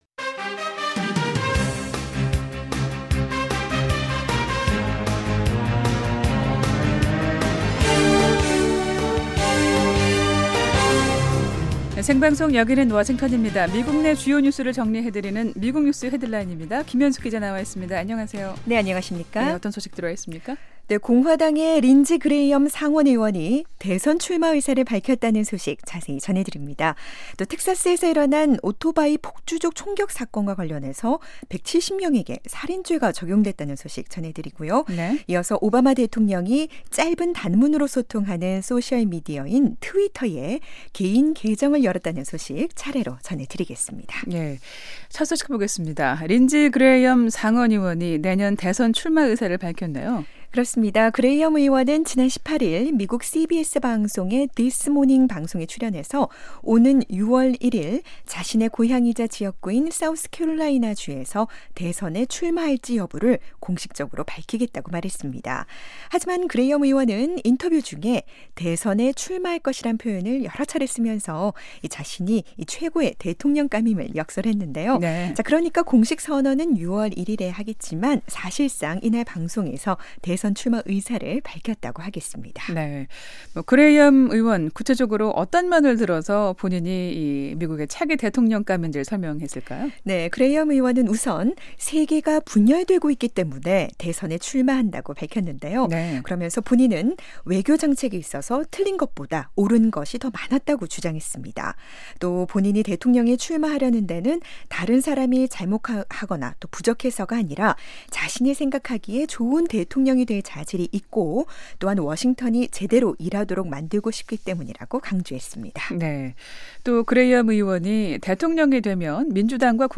미국 내 주요 뉴스를 정리해 드리는 ‘미국 뉴스 헤드라인’입니다. 공화당의 린지 그레이엄 상원의원이 대선 출마 의사를 밝혔다는 소식 자세히 전해드립니다.